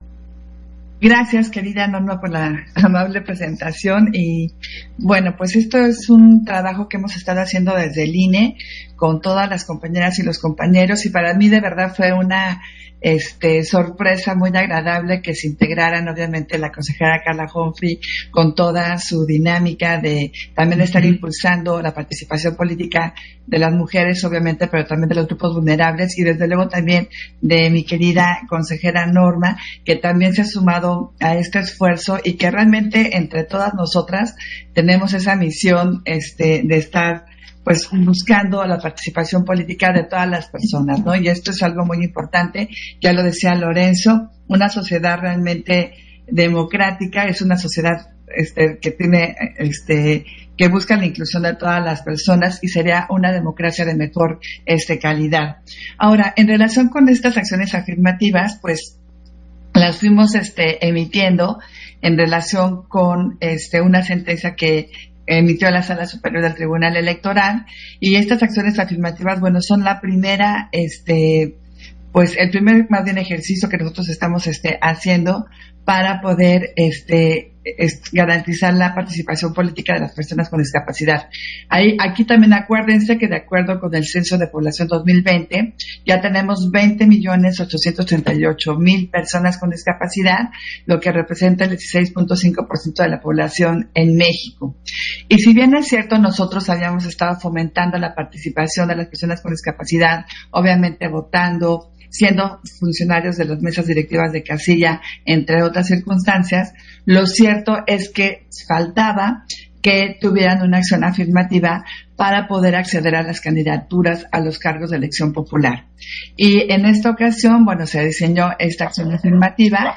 Intervención de Adriana Favela, en el panel Retos y avances de la participación de las personas con discapacidad en el proceso electoral 2021, Conversatorio de las Acciones Afirmativas a la Acción